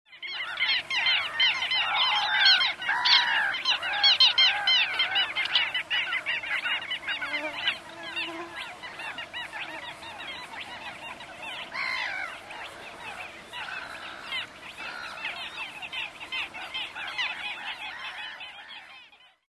Gavina petita (Larus minutus)
larus-minutus.mp3